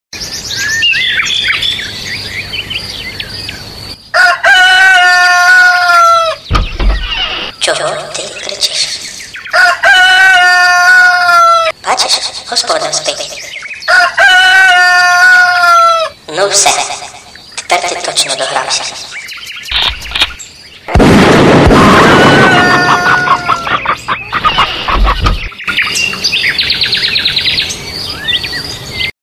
Тип: рінгтони
budulnuk_piven.mp3 [458 Kb] (Завантажень: 131)